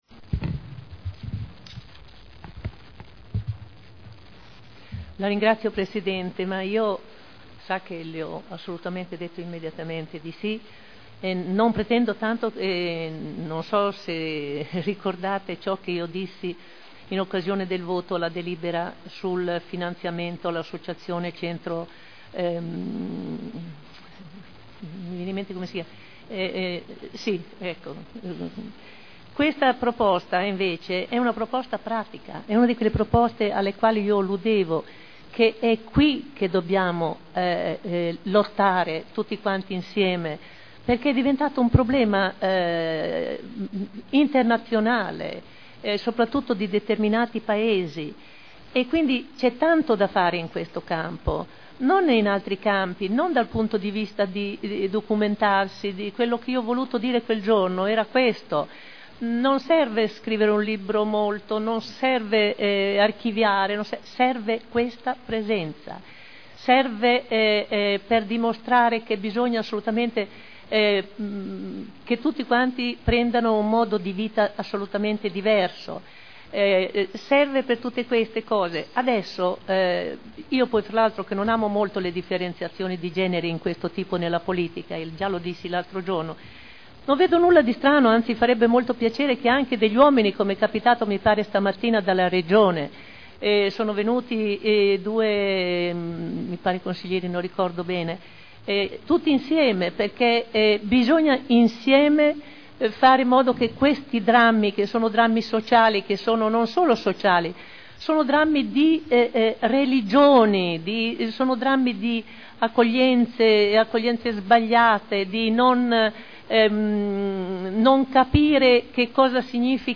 Olga Vecchi — Sito Audio Consiglio Comunale
Dibattito su comunicazione di solidarietà per le vittime della tragedia di Novi di Modena